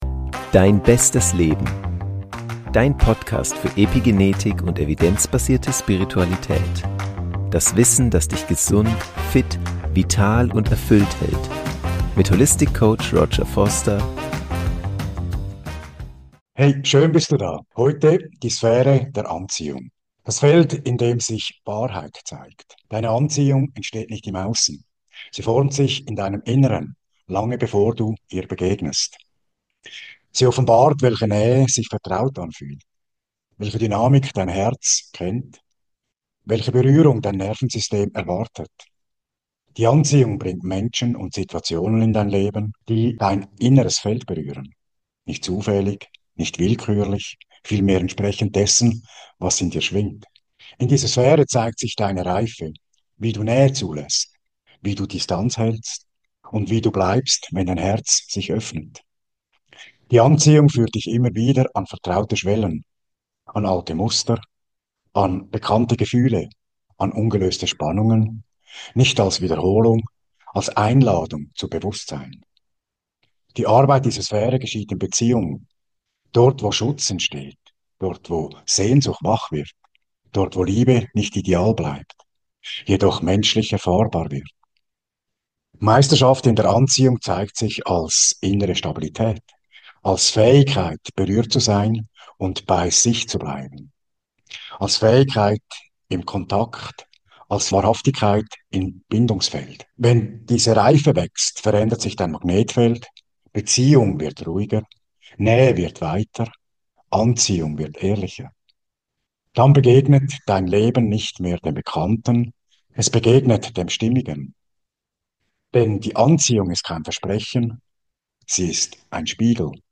Nach einem kurzen Intro führt Dich eine kontemplative Meditationsreise in Dein eigenes Beziehungsfeld. Sanft, ehrlich und regulierend. Du erkundest, wie Nähe sich in Deinem Körper anfühlt und wie innere Reife Dein Magnetfeld verändert.
Am besten mit Kopfhörern hören, um das feine Beziehungsfeld gut wahrzunehmen.